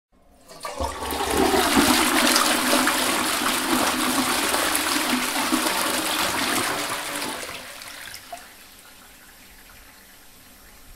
جلوه های صوتی
دانلود صدای آب دستشویی از ساعد نیوز با لینک مستقیم و کیفیت بالا